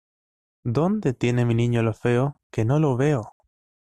Read more Det Noun Noun Frequency A1 Pronounced as (IPA) /mi/ Etymology From Latin meus, when it was eliding before a vowel-initial word in speech.